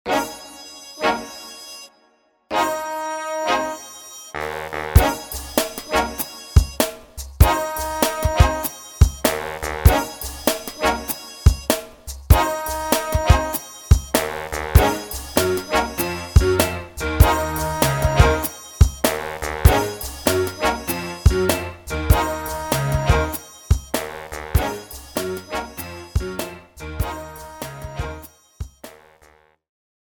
blues_02.mp3